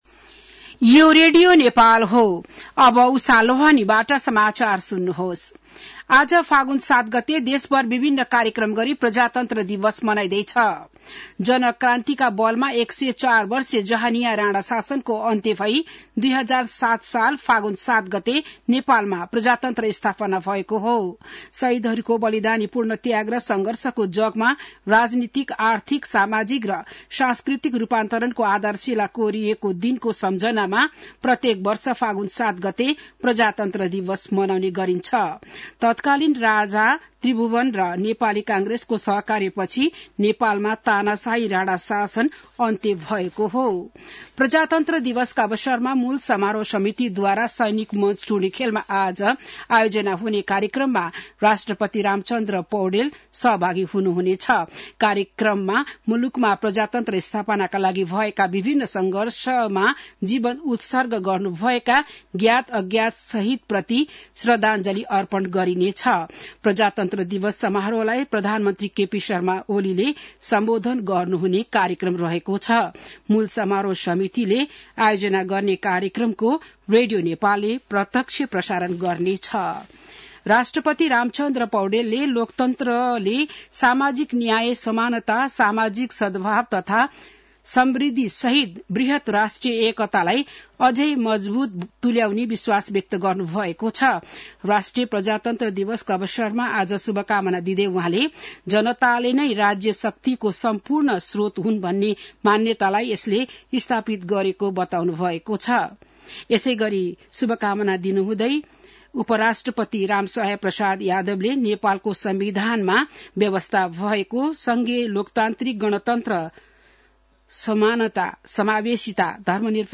बिहान १० बजेको नेपाली समाचार : ८ फागुन , २०८१